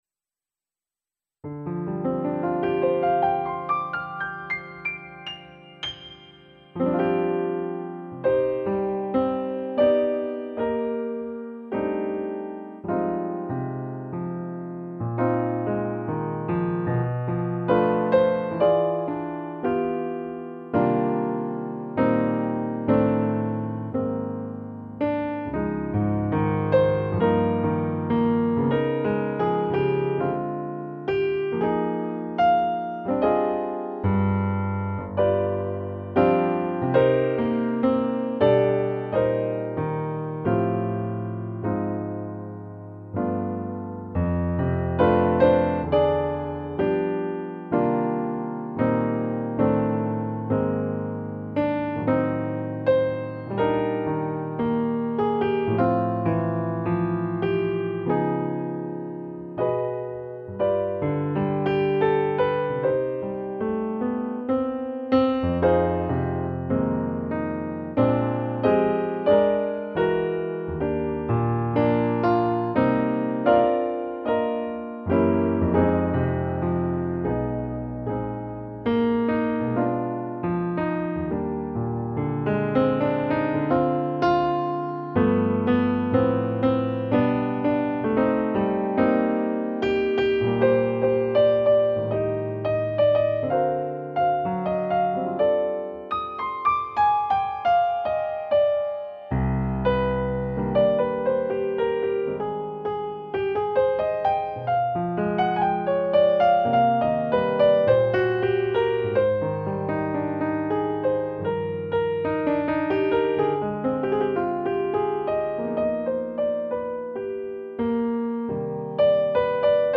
piano arrangement